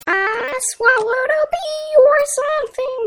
Tags: auto tune